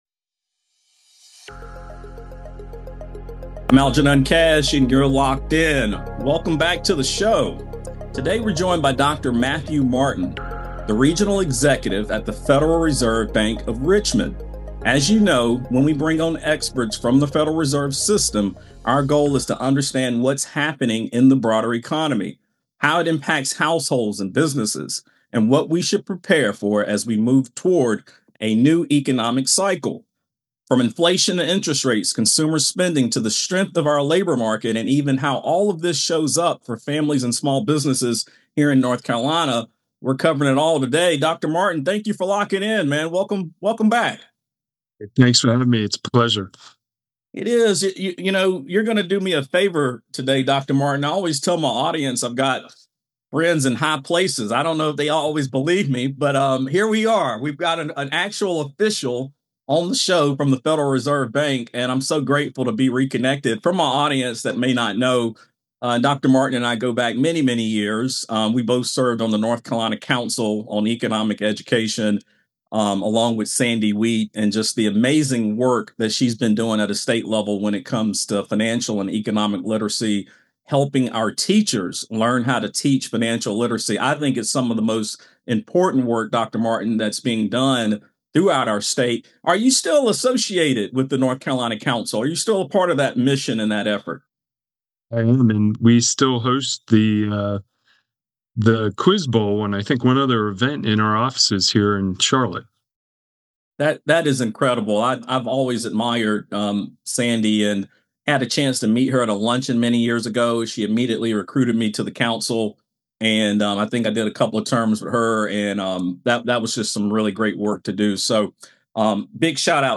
Uncertainty in the Economy: A Conversation